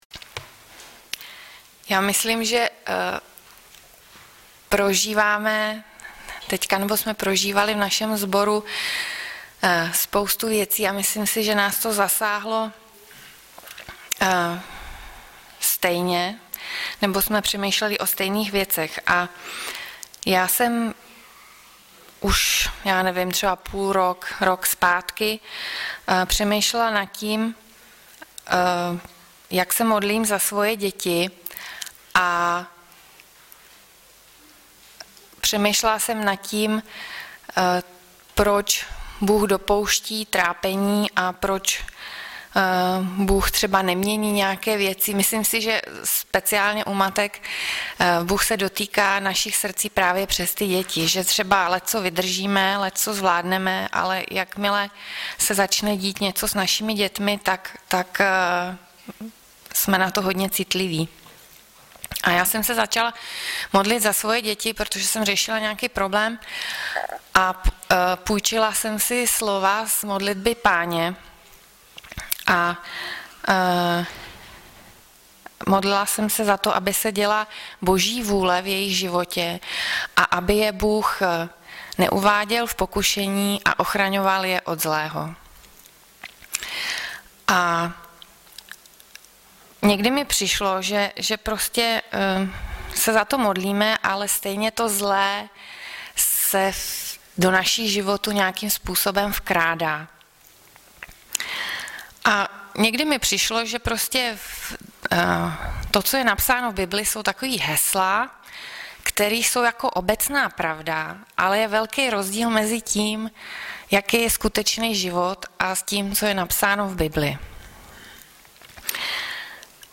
Hlavní nabídka Kázání Chvály Kalendář Knihovna Kontakt Pro přihlášené O nás Partneři Zpravodaj Přihlásit se Zavřít Jméno Heslo Pamatuj si mě  23.11.2014 - BIBLICKÉ ÚVAHY SESTER Audiozáznam kázání si můžete také uložit do PC na tomto odkazu.